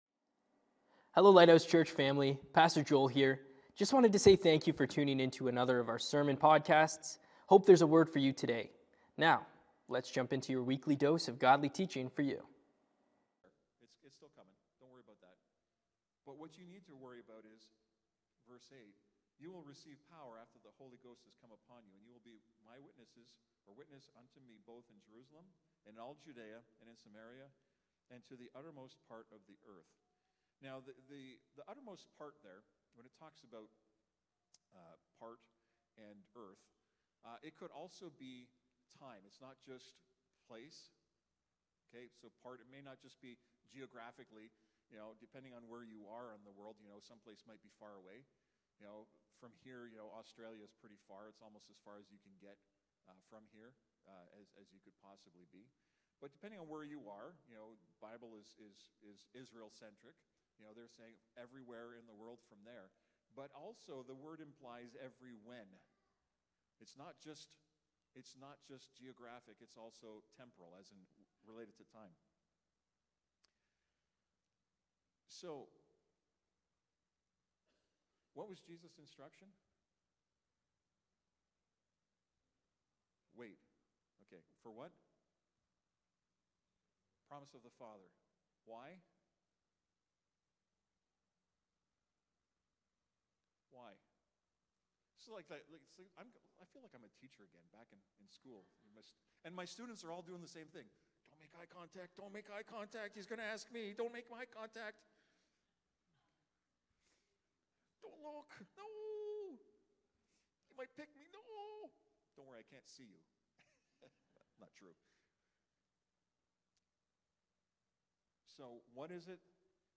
Lighthouse Niagara Sermons Have You Received The Holy Spirit?